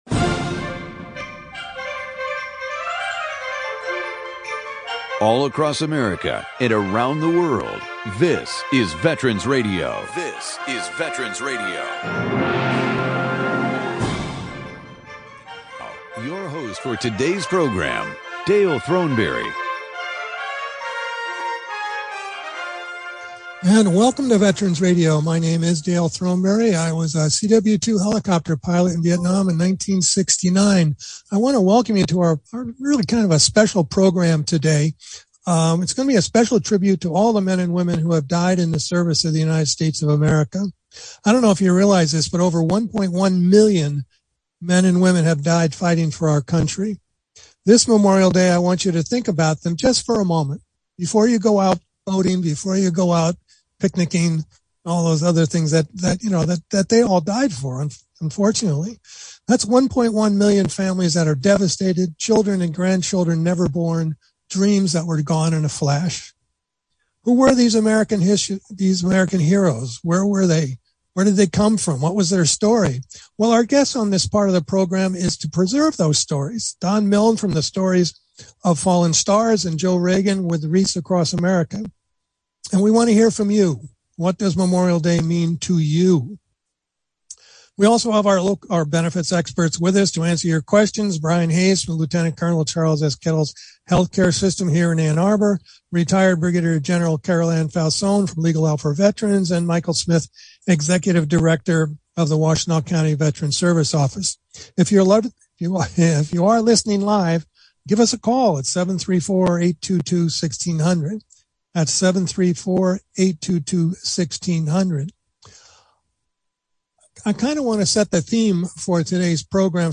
Call in during the program to ask your question or offer a comment.